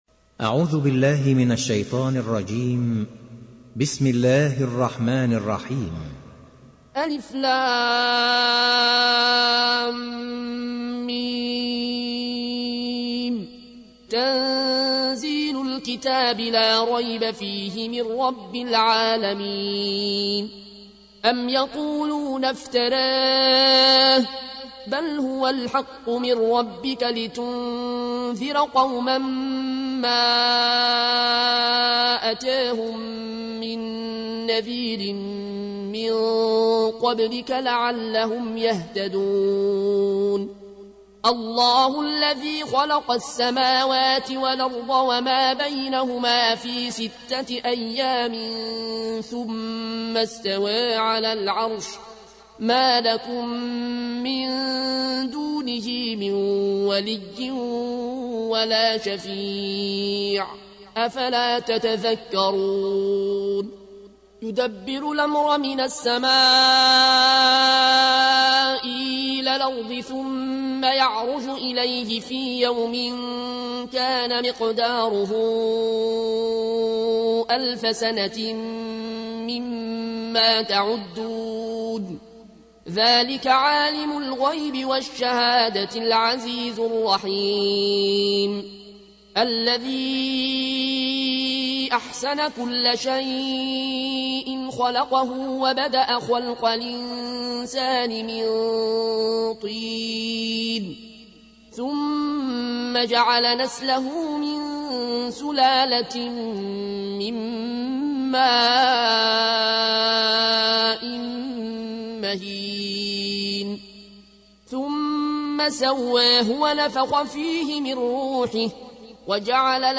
تحميل : 32. سورة السجدة / القارئ العيون الكوشي / القرآن الكريم / موقع يا حسين